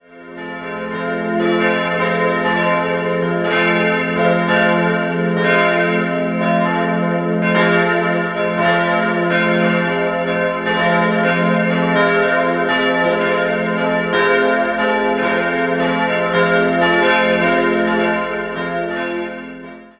Die Johanniskirche wurde in den Jahren 1896/97 errichtet und einfach, aber geschmackvoll ausgestattet. Das mittlere große Fenster im Altarraum zeigt den auferstandenen Christus. 3-stimmiges F-Dur-Geläut: f'-a'-c'' Die Glocken wurden im Jahr 2003 von der Gießerei Lauchhammer gegossen.